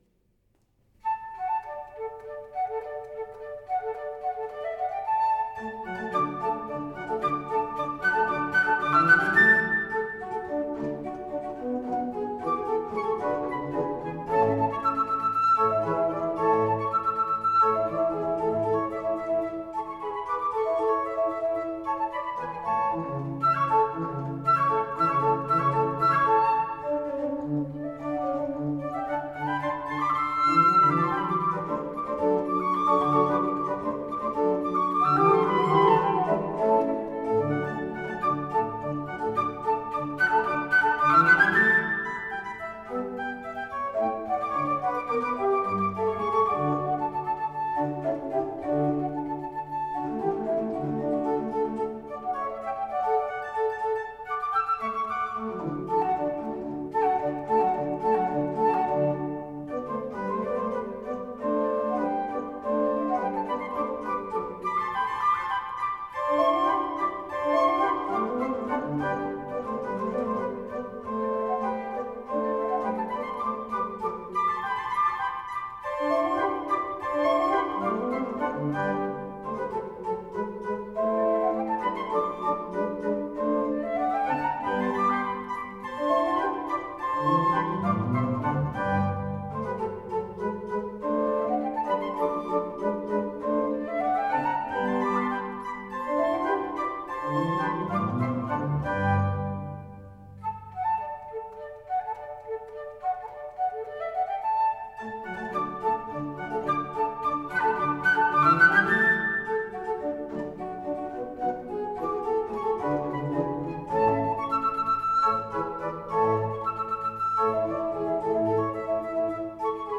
Aus der Serenade op. 41 für Flöte und Orgel